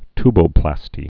(tbō-plăstē, ty-)